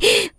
gasp.wav